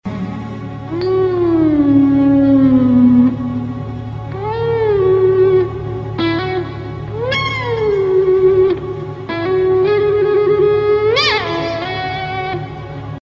vibrato.mp3